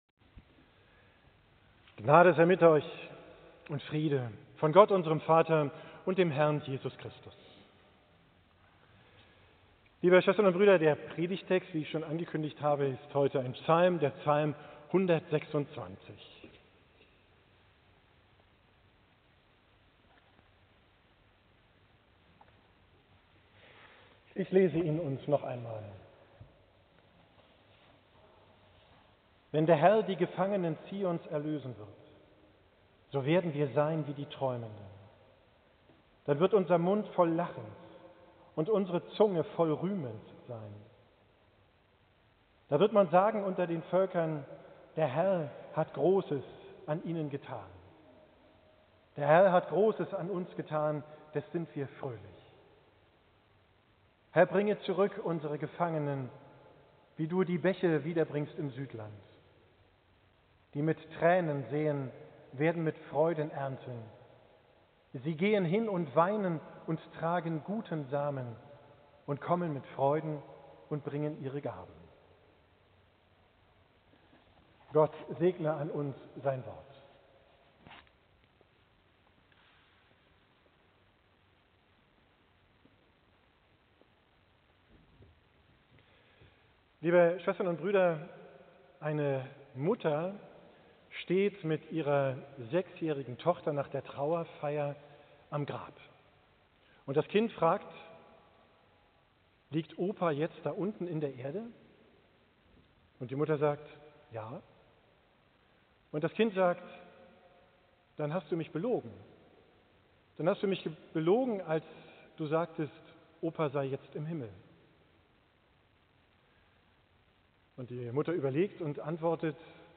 Predigt am Ewigkeitssonntag, 24.